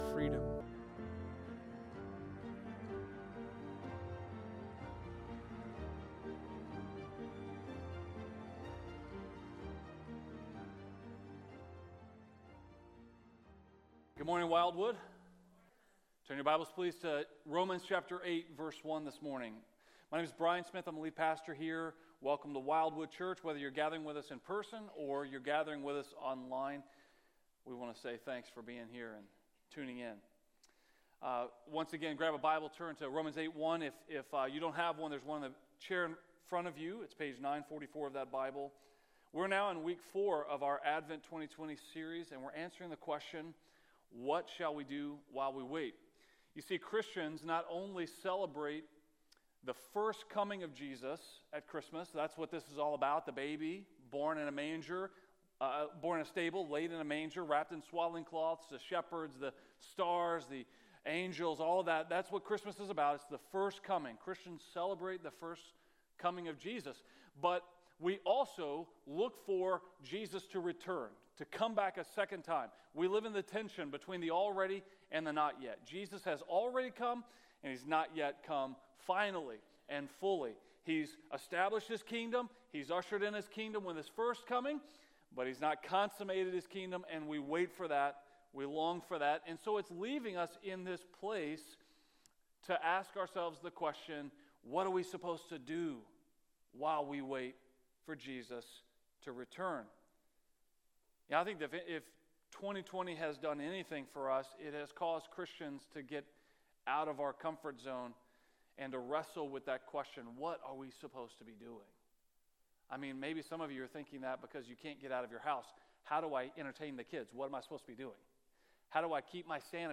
A message from the series "Promise of Christmas."